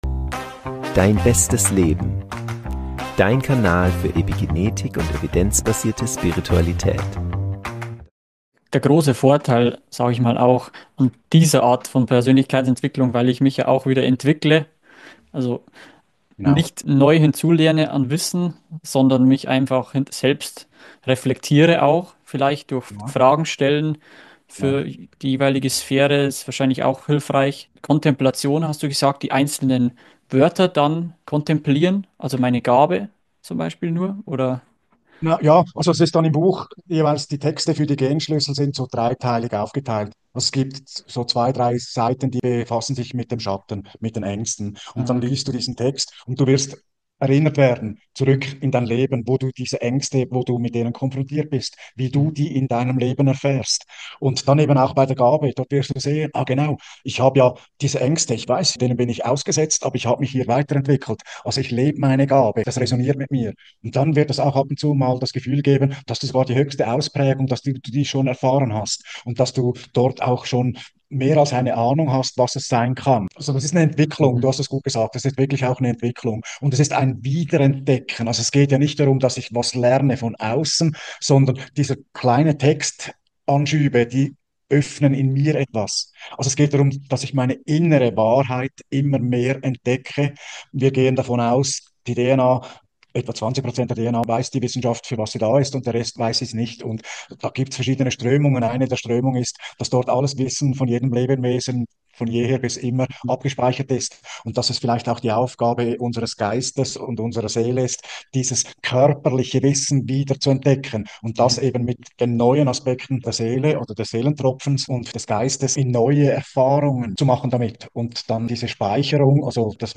Du lernst die Methode der Kontemplation kennen und welche Bedeutung die Sphären der Aktivierungssequenz und der Venussequenz auf Deiner Reise haben. Mit den 64 Genschlüsseln entfaltest Du Dein Potenzial und transformierst Deine Schatten, sodass Du Meisterschaft in den vier großen Lebensbereichen Gesundheit, Berufung, Beziehungen und Lebenssinn erlangen kannst. Das Interview wurde für den Selbstbestimmungs-Kongress aufgenommen, der Menschen das Wissen in den großen Lebensbereichen vermittelt, mit denen sie ihr Leben selbstbestimmt gestalten können.